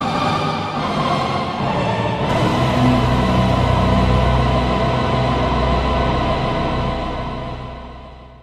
Efectos de sonido